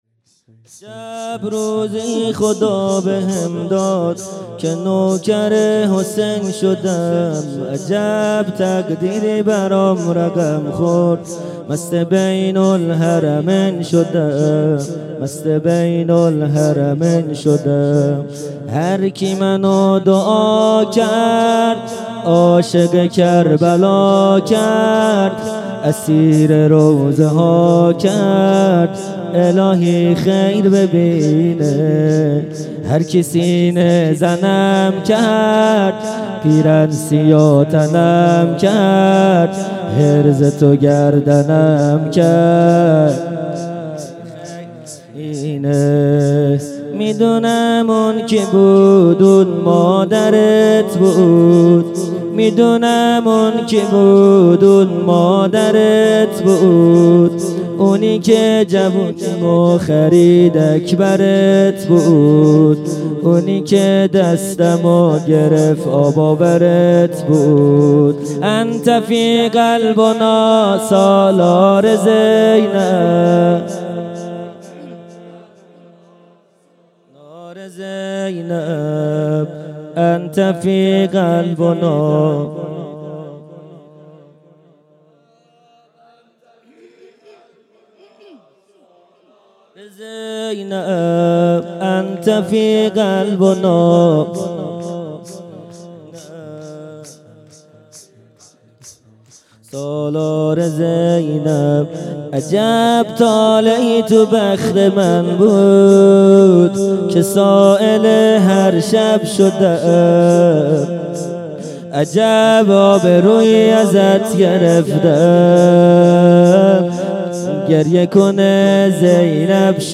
شور | عجب روزی خدا بهم داد که نوکر حسین شدم
عزاداری فاطمیه اول | شب دوم 29 دی 1397